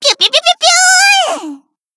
BA_V_Koyuki_Battle_Shout_1.ogg